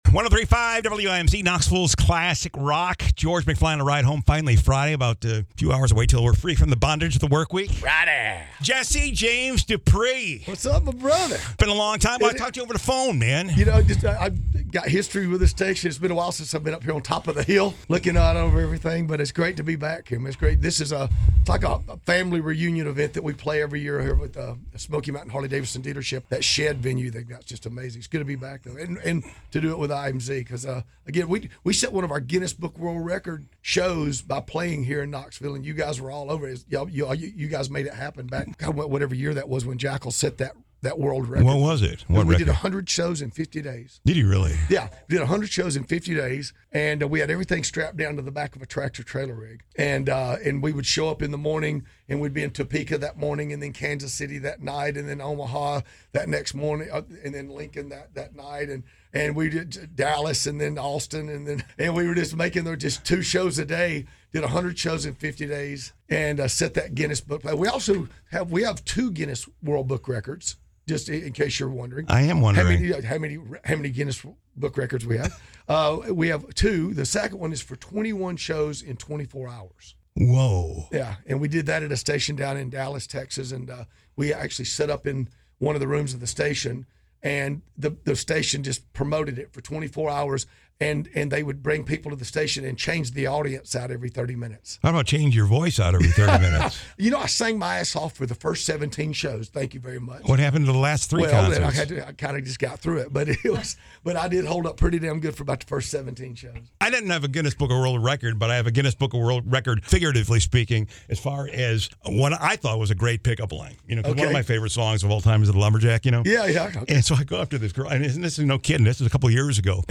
Jesse James Dupree stopped by the studio today to plug his concert at the Shed in Maryville tomorrow night!